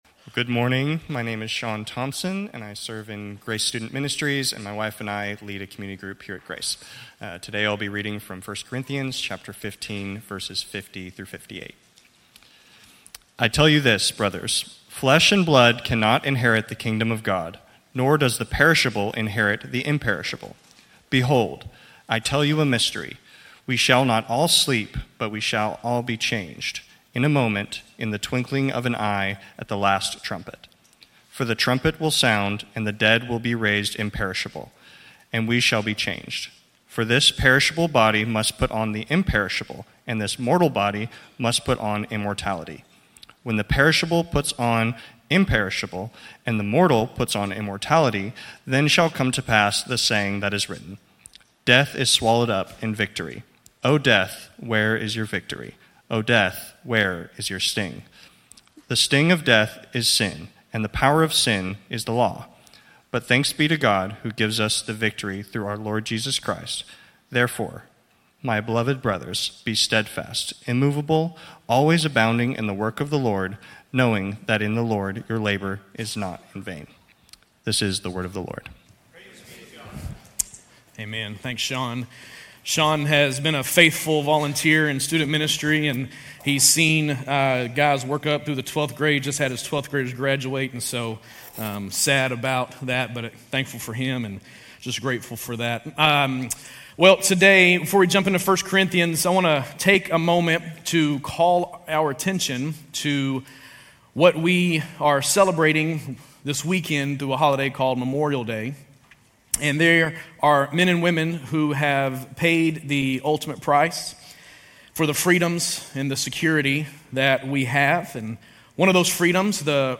Grace Community Church University Blvd Campus Sermons 5_25 University Blvd Campus May 26 2025 | 00:28:15 1x 00:00 / 00:28:15 Subscribe Share RSS Feed Share Link Embed